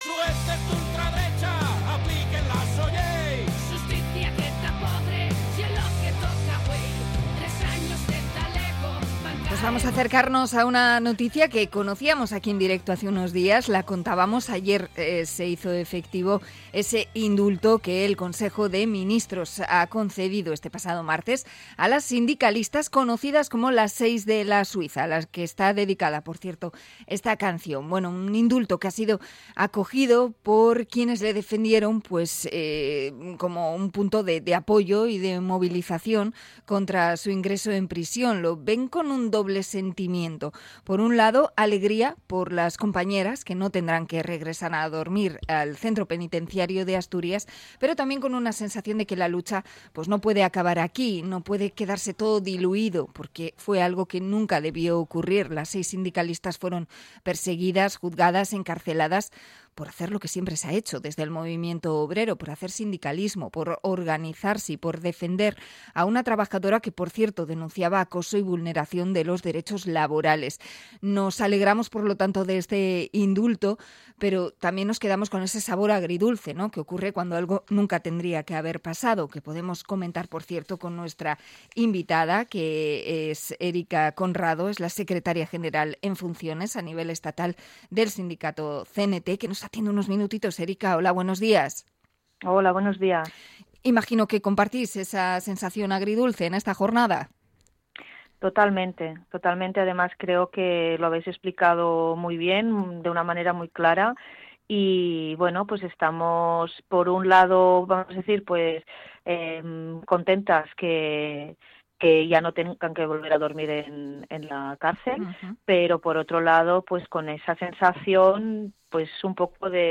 Entrevista a la CNT por el indulto a "Las seis de la Suiza"